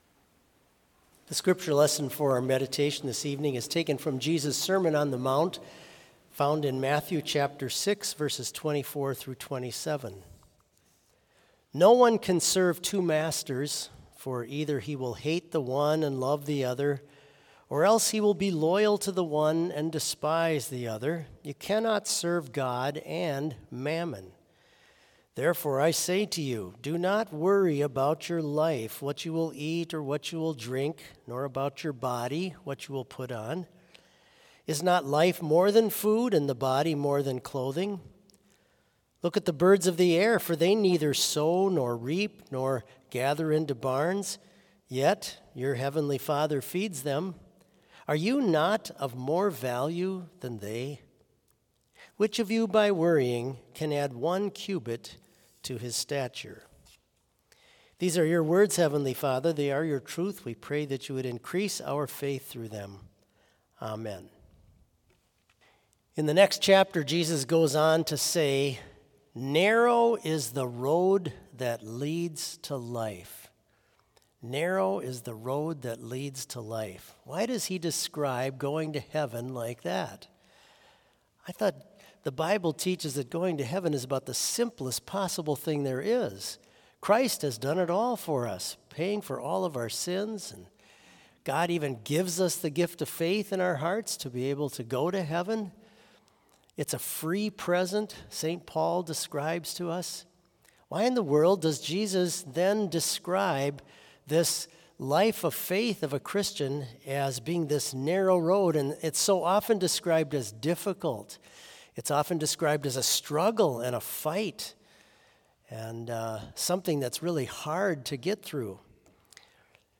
Vespers in Trinity Chapel, Bethany Lutheran College
Choral Psalmody
Homily
choral setting
Complete service audio for Vespers - Wednesday, September 11, 2024